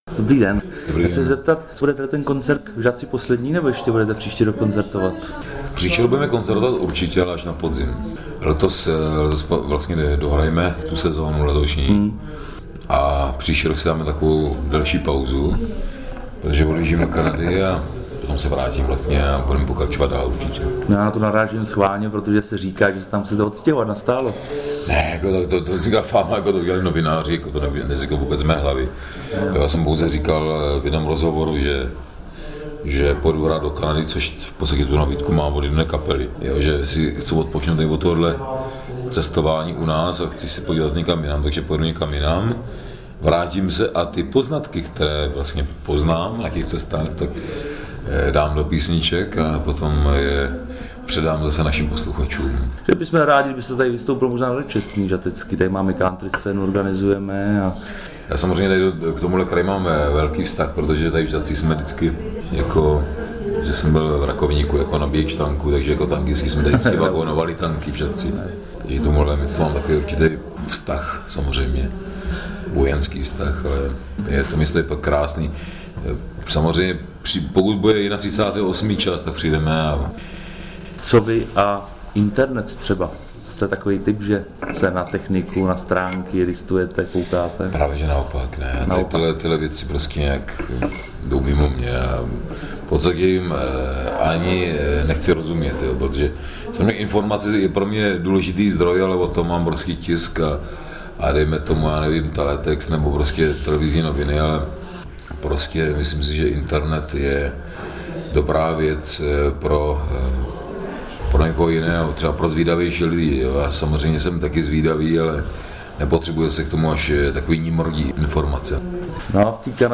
Všechny rozhovory jsem pořídil v Žateckém divadle před vystoupením interpretů.
ROZHOVOR Přepis rozhovoru Ø145kbps/44 khz 3702KB